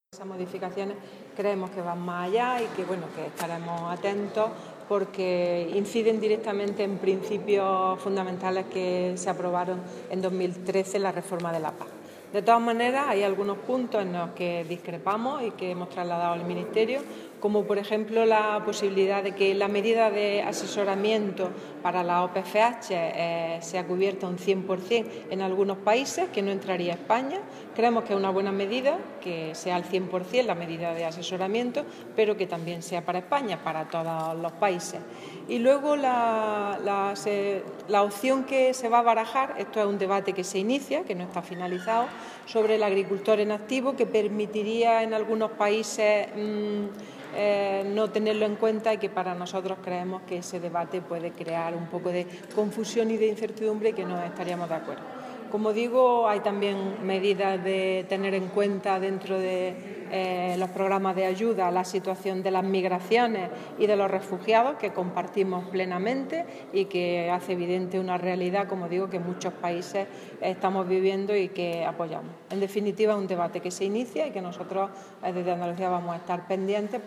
Declaraciones de Carmen Ortiz sobre la propuesta de revisión del Marco Financiero Plurianual